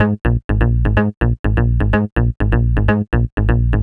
cch_bass_loop_johnson_125_Ab.wav